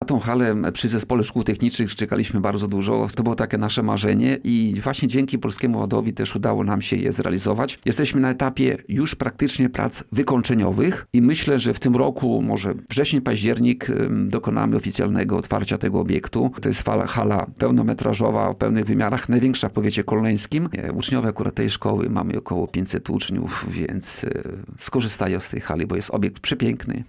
Jak mówił na antenie Radia Nadzieja starosta kolneński Tadeusz Klama, budowana hala to jedna z największych inwestycji w powiecie.